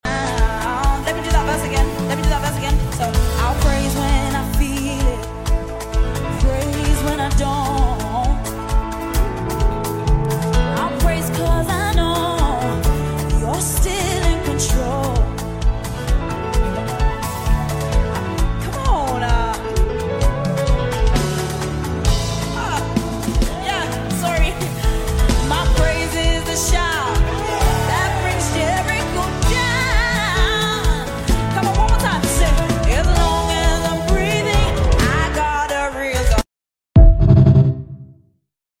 At soundcheck the other day sound effects free download
just wanted to fill in the gap with something melodious and simple!
Whatever the drummer was doing 🤷🏻‍♂😒😒.. man’s trying to get some shine!